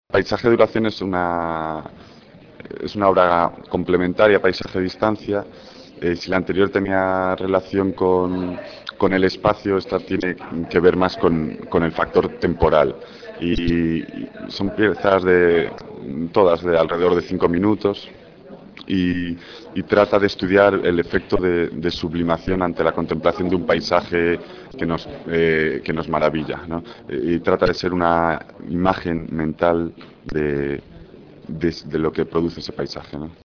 Entrevista en audio